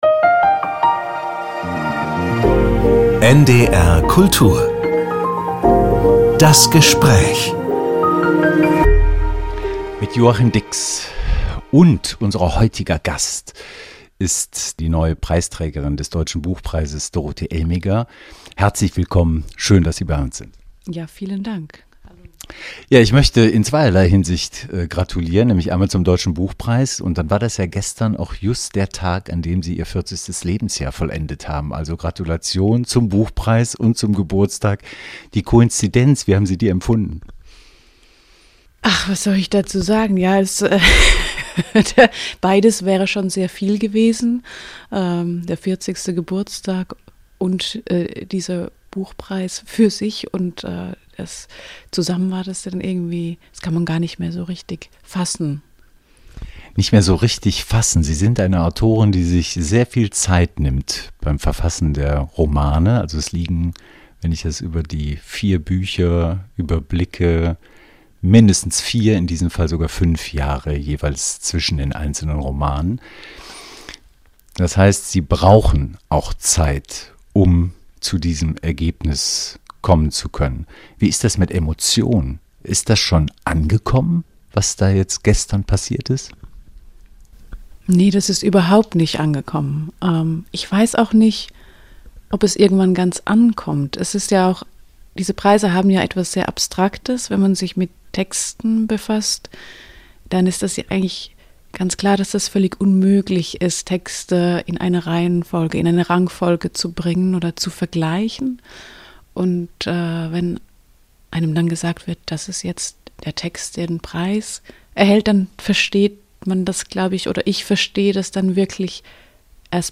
die Schweizer Schriftstellerin Dorothee Elmiger.